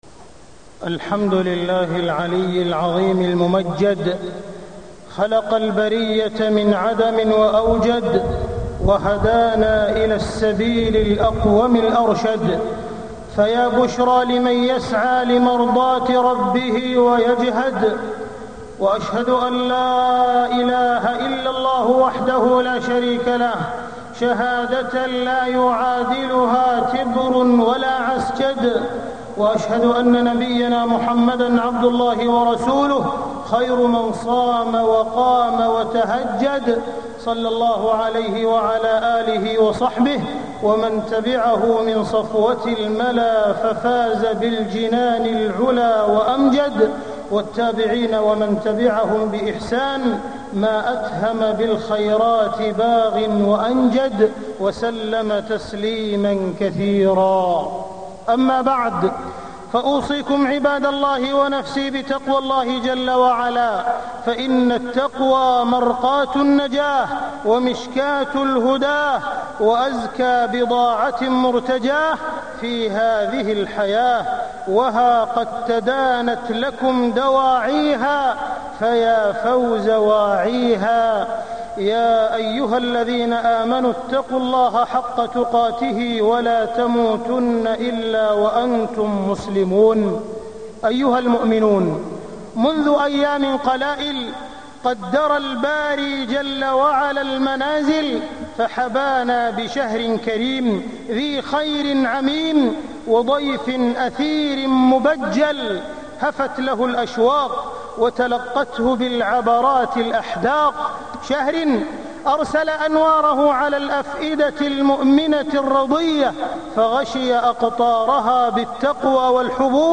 تاريخ النشر ٨ رمضان ١٤٢٥ هـ المكان: المسجد الحرام الشيخ: معالي الشيخ أ.د. عبدالرحمن بن عبدالعزيز السديس معالي الشيخ أ.د. عبدالرحمن بن عبدالعزيز السديس رمضان وحال الأمة